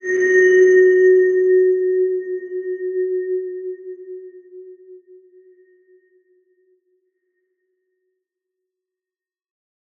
X_BasicBells-F#2-ff.wav